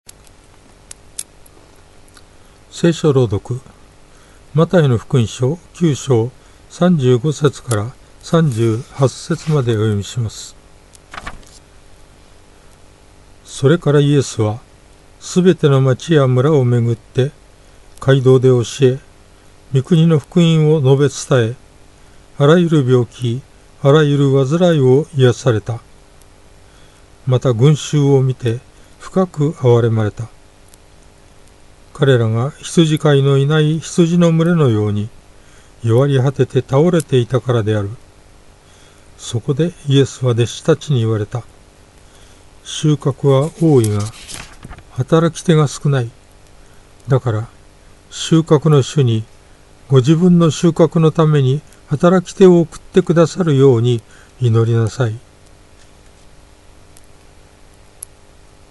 BibleReading_Math9.35-38.mp3